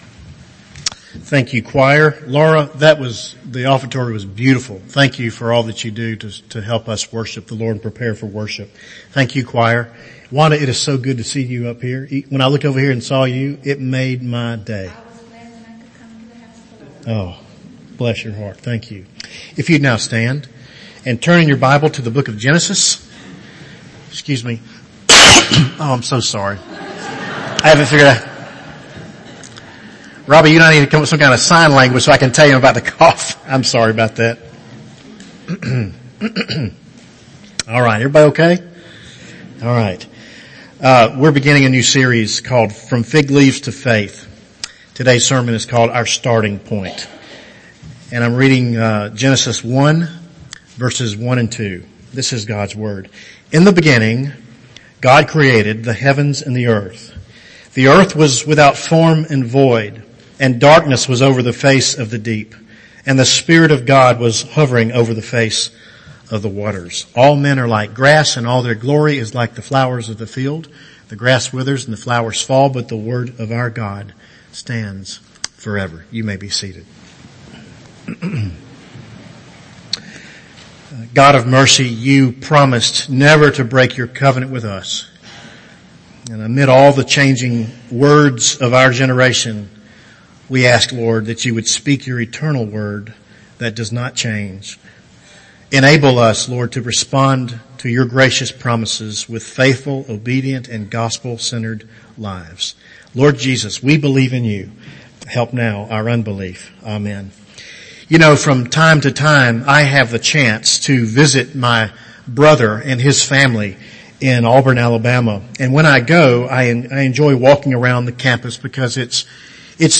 For those of you enjoying the Sunday sermons...